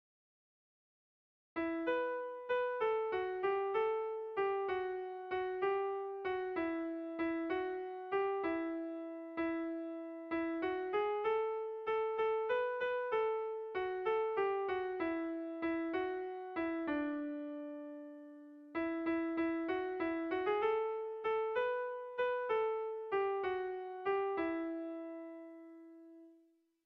Melodías de bertsos - Ver ficha   Más información sobre esta sección
ABDE